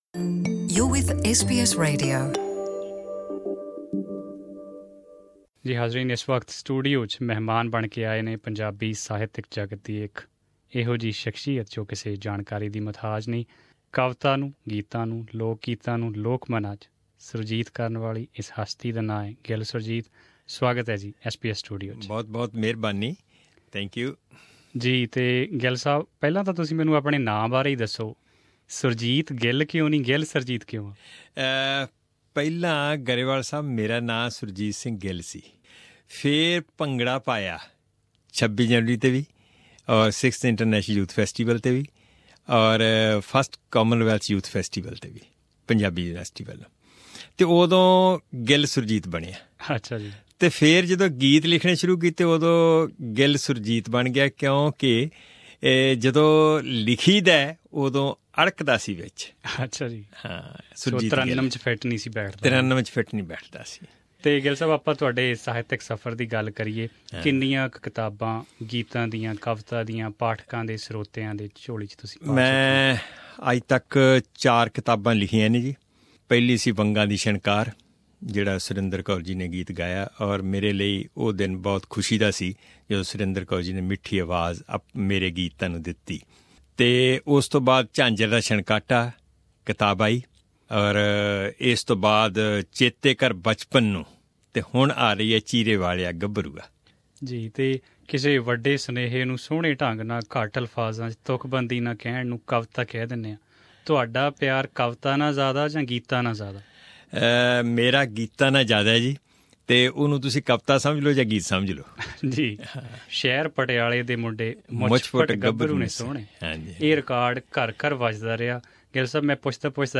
Tune into this interview that we recorded with him in 2014 at SBS studio in Melbourne.